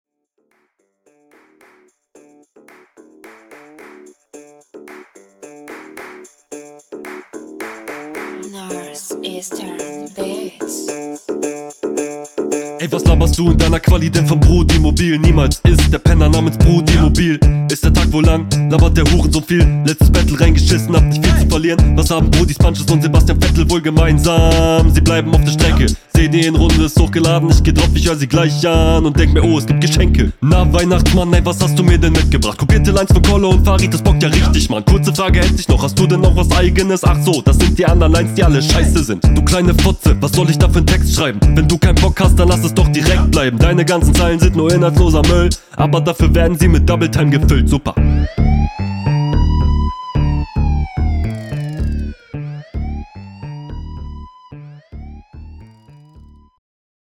Stimmlich klingt das schonmal viel motivierter.
Flowlich ganz geil, gerade die lang gezogenen Sachen.